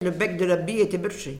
Mémoires et Patrimoines vivants - RaddO est une base de données d'archives iconographiques et sonores.
Localisation Saint-Hilaire-des-Loges
Catégorie Locution